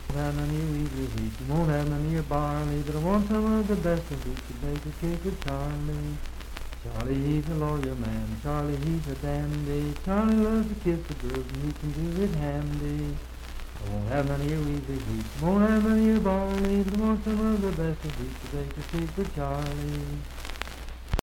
Unaccompanied vocal music
Dance, Game, and Party Songs
Voice (sung)
Marlinton (W. Va.), Pocahontas County (W. Va.)